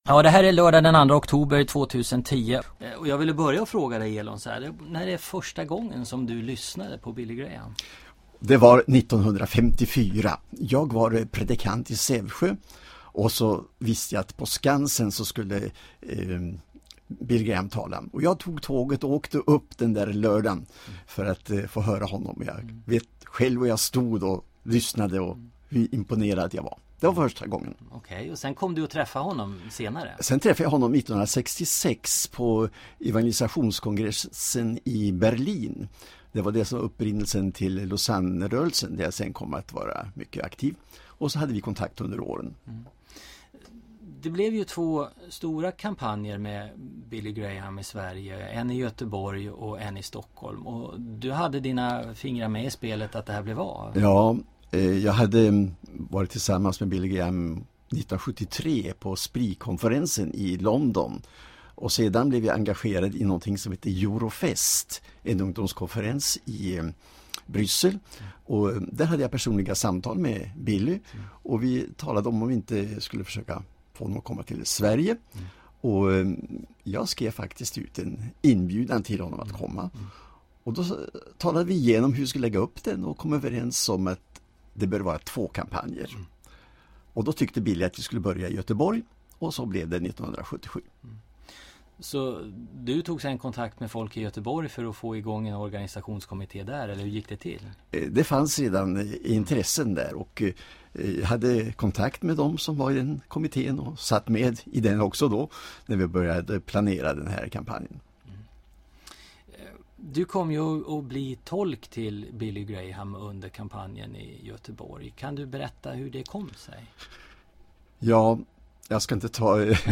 Inspelat samtal den 1 oktober 2010.